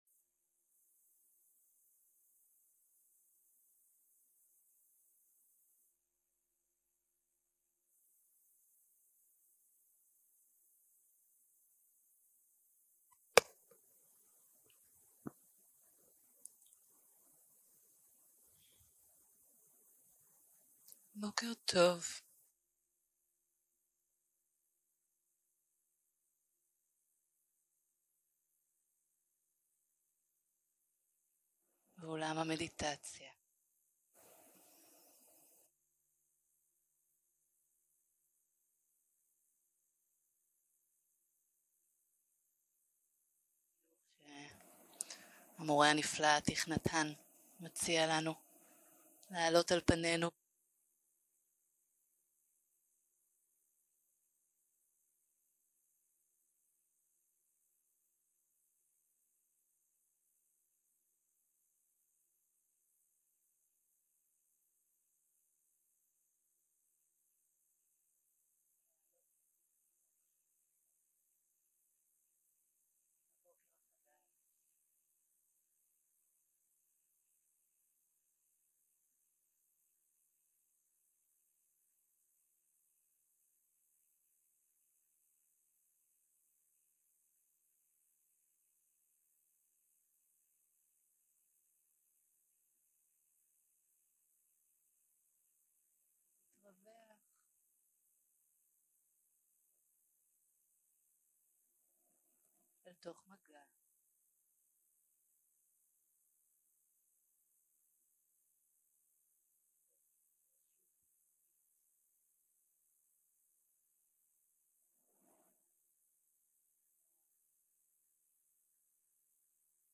יום 3 - הקלטה 6 - בוקר - מדיטציה מונחית
סוג ההקלטה: מדיטציה מונחית